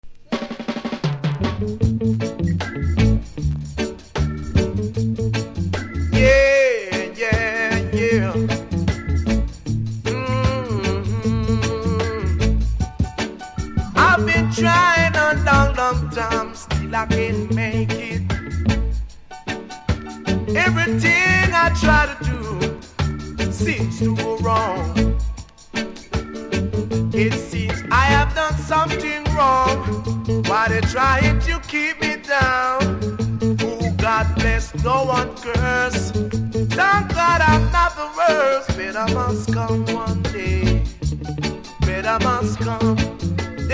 多少うすキズありますが音は良好なので試聴で確認下さい。